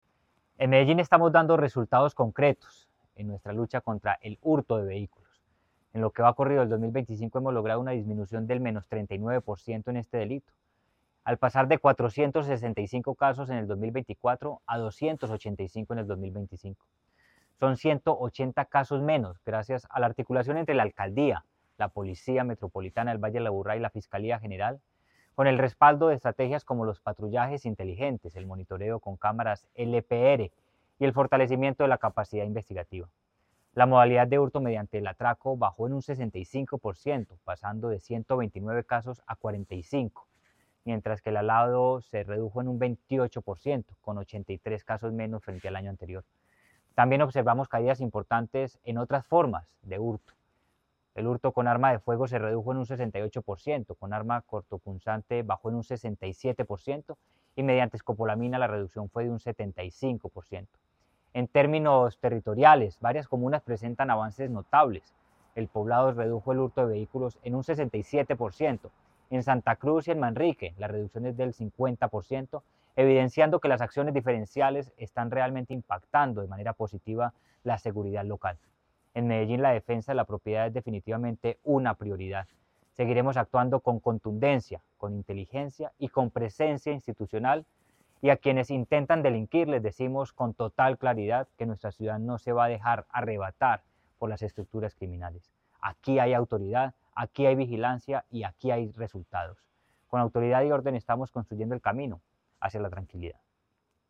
Declaraciones secretario de Seguridad y convivencia de Medellín, Manuel Villa Mejía.
Declaraciones-secretario-de-Seguridad-y-convivencia-de-Medellin-Manuel-Villa-Mejia..mp3